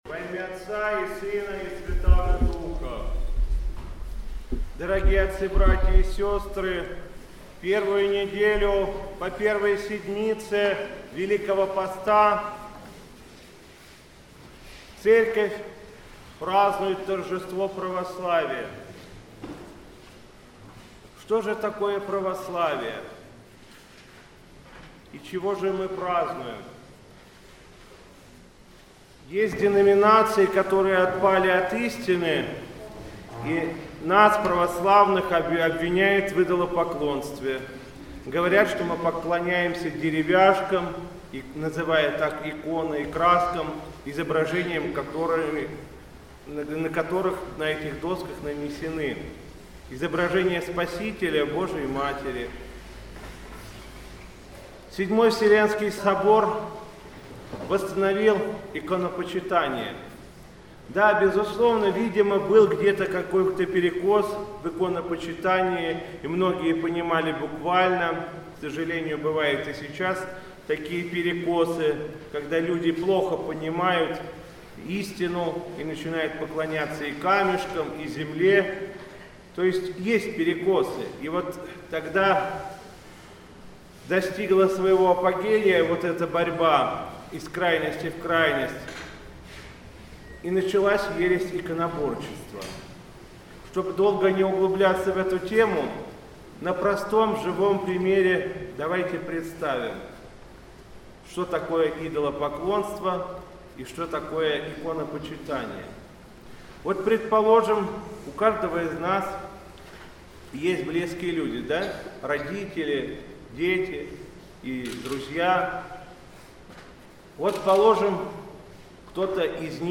Затем митрополит Игнатий обратился к присутствующим с архипастырским словом на тему истины и Торжества Православия.